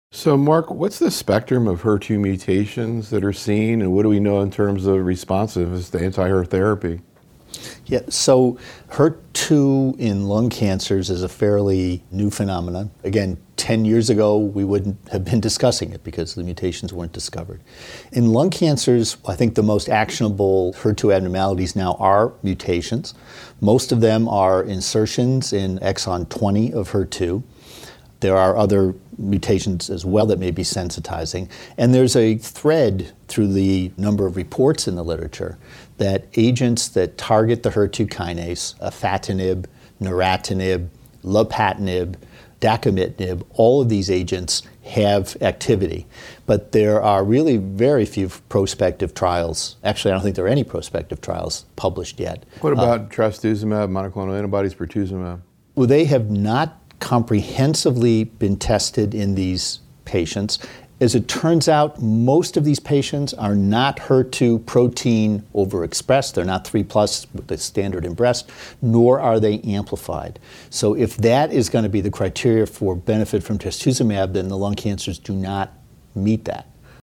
In these audio proceedings from a closed Think Tank meeting held in January 2015, the invited faculty discuss and debate some of the key clinical management issues in lung cancer as well as promising research strategies in this area. The roundtable discussion focused on key presentations and papers and actual cases managed in the practices of the faculty in which these data sets factored into their decision-making.